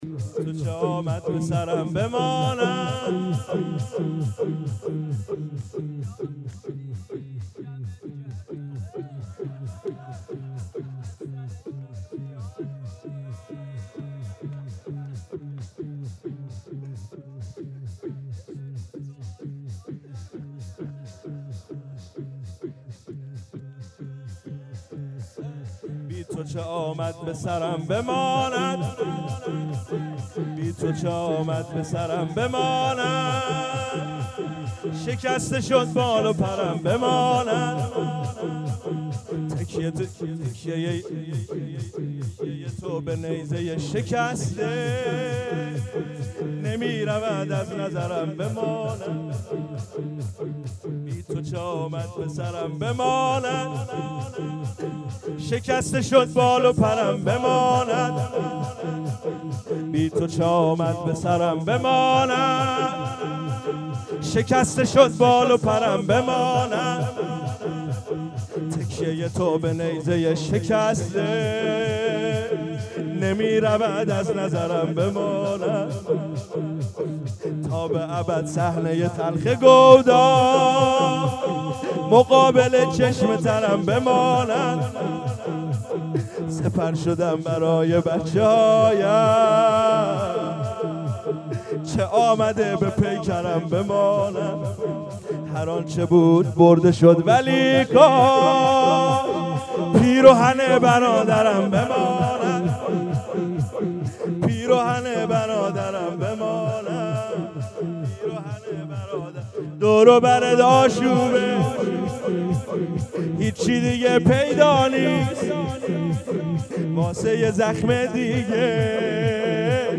شور روضه |که چه آمَد به سِرم به‌مانَد
مراسم شام غریبان